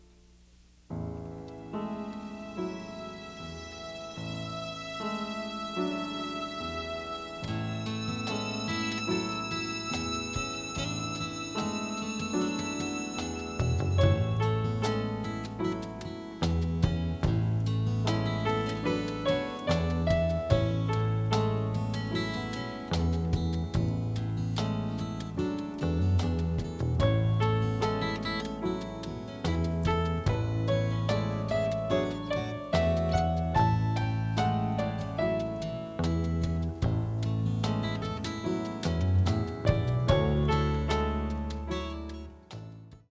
With its jazzy elements